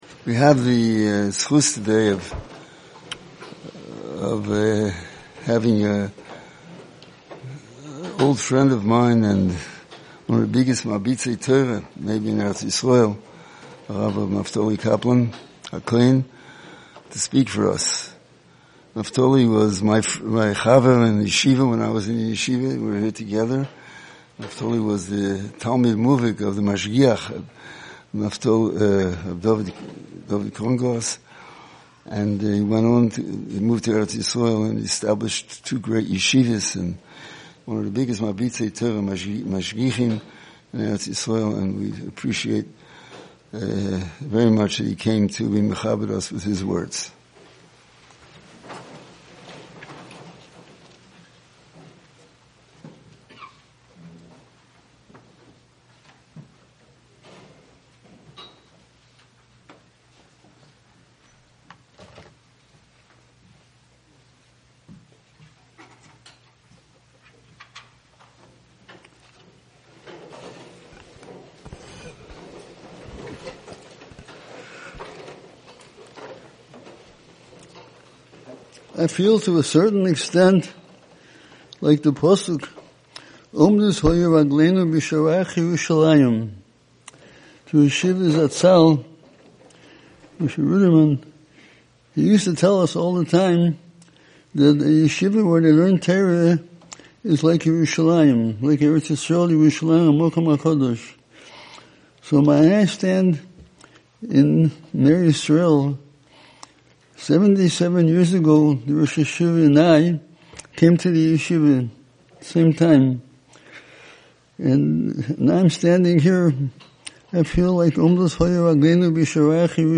Special Shiur - Ner Israel Rabbinical College